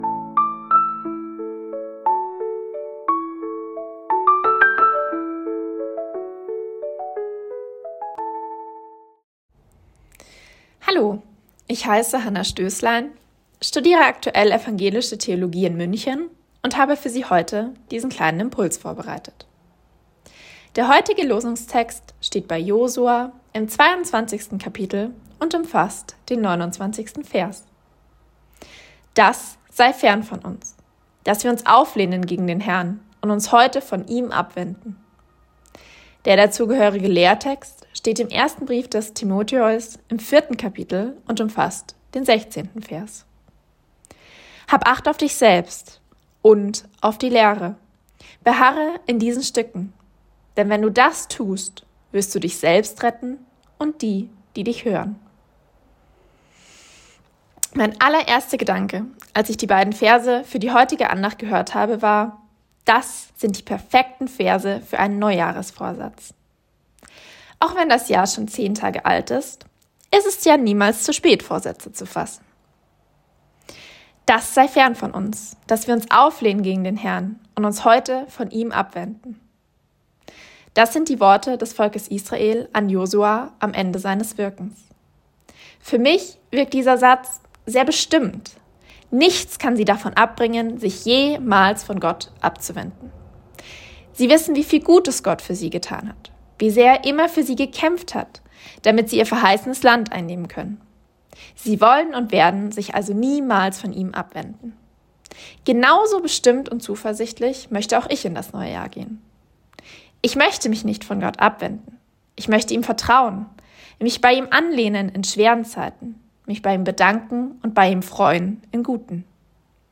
Losungsandacht für Samstag, 10.01.2026
Text und Sprecherin